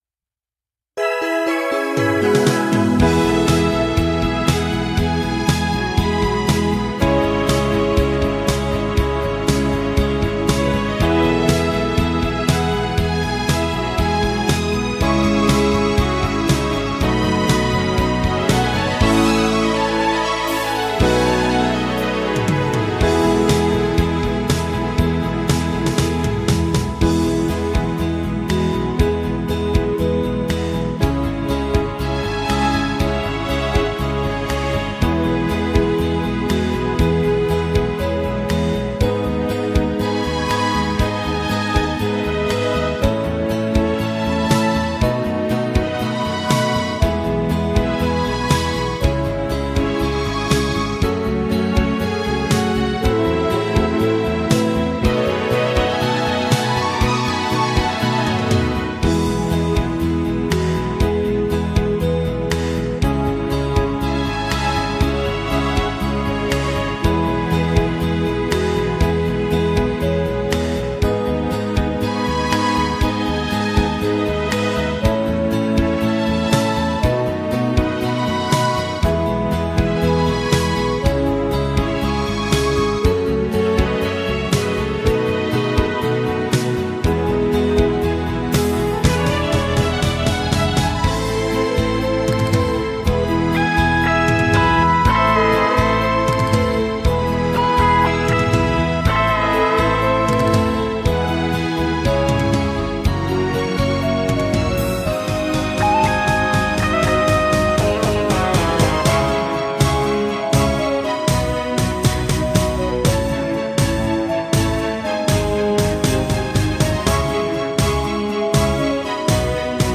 鼻笛練習用音楽素材
鼻笛教室等で使用の練習用素材はこちらにまとめます。
熱き心に　伴奏